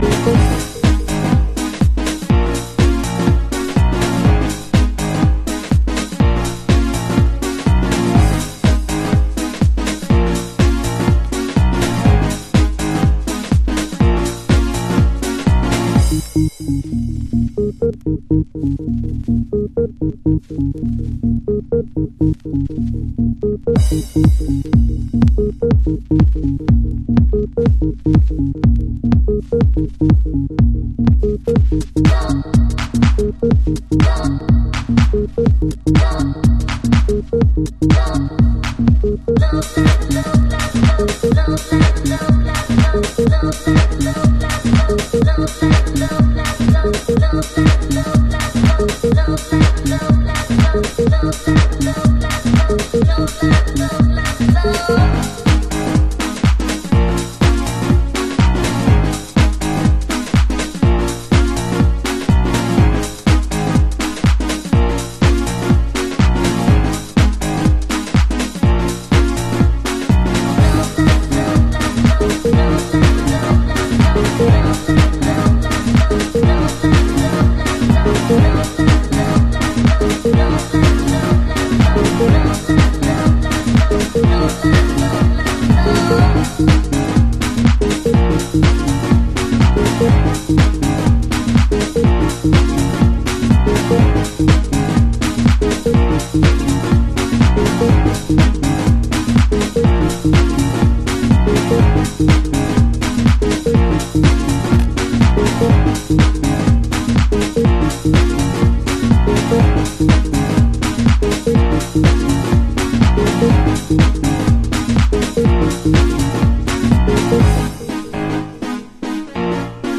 House / Techno
ベースが強調されたディープハウス〜ガラージハウス。